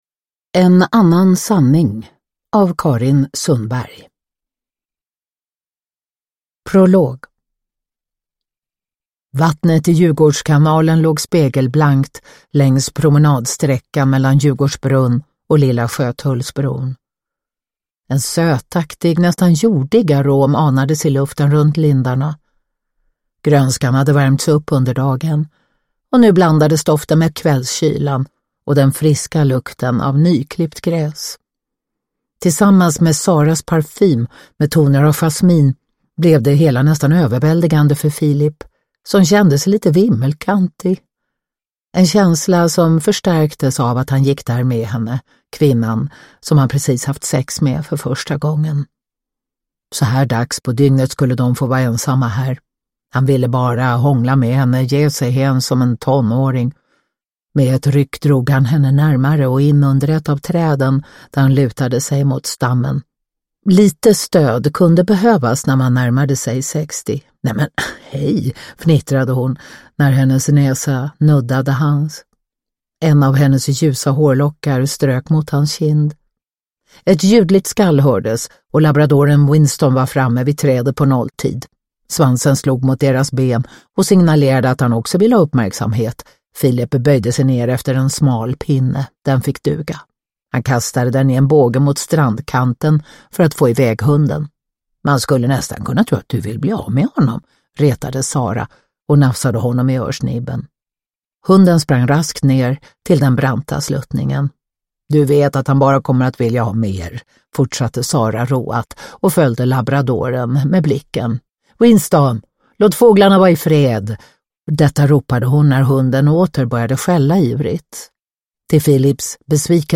En annan sanning – Ljudbok
Uppläsare: Katarina Ewerlöf